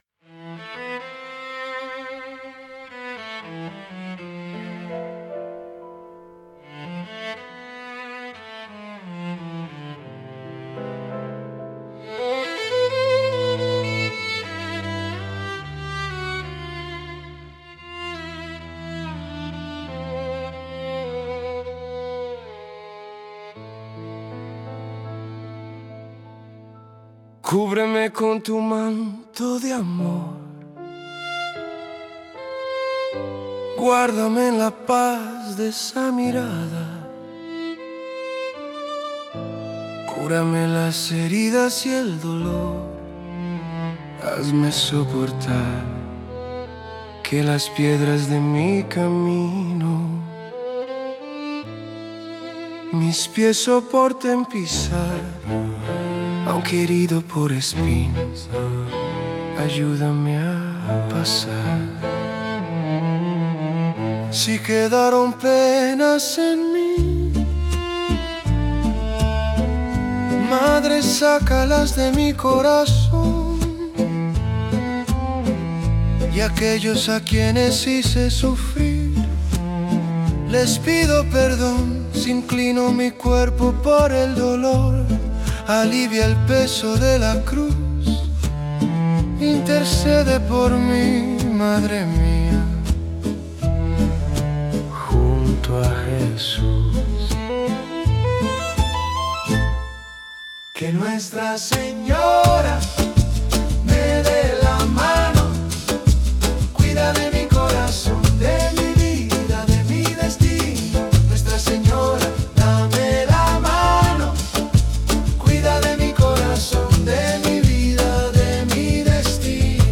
música e arranjo: IA) instrumental 6